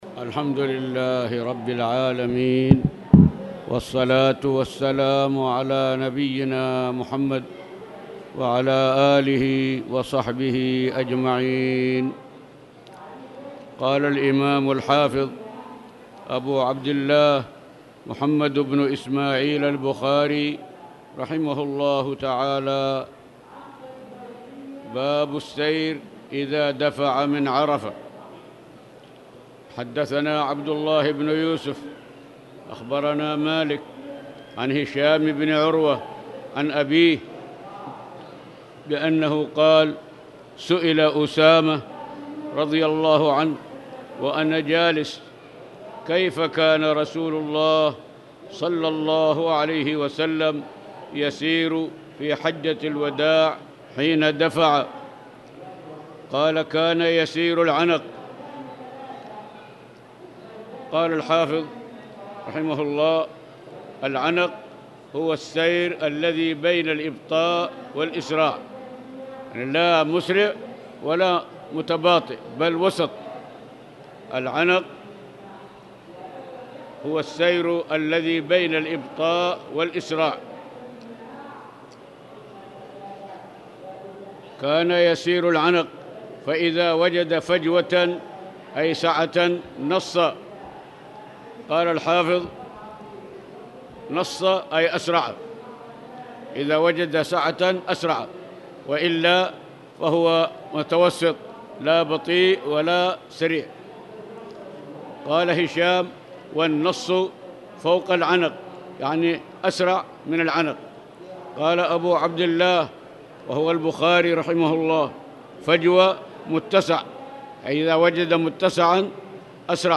تاريخ النشر ١٦ صفر ١٤٣٨ هـ المكان: المسجد الحرام الشيخ